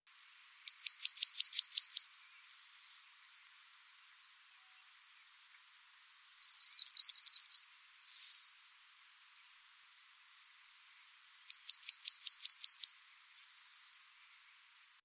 In der Schweiz sind fast 40 Prozent aller Vogelarten vom Aussterben bedroht. Wie lange ist die charaktervolle Strophe der Goldammer wohl noch zu hören?
Singende Goldammer
goldammer_singend.mp3